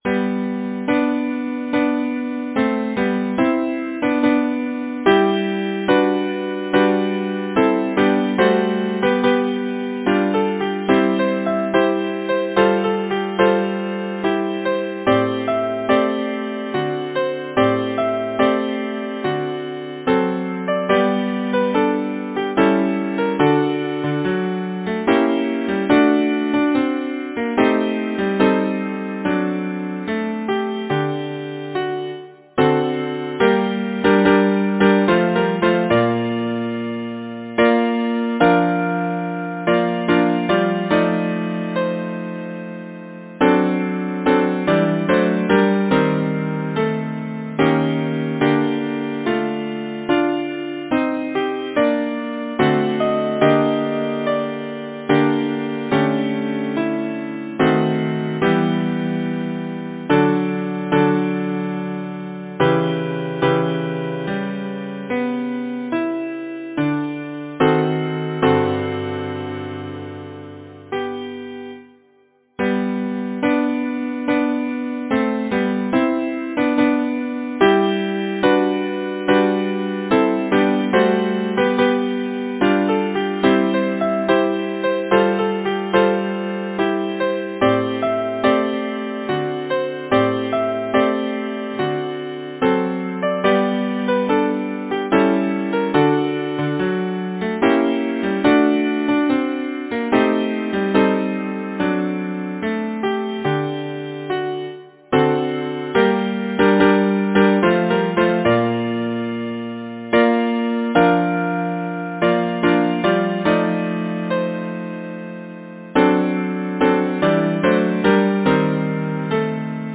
Title: To the Spring Wind Composer: Eduard Hecht Lyricist: Edwin Waugh Number of voices: 4vv Voicing: SATB Genre: Secular, Partsong
Language: English Instruments: A cappella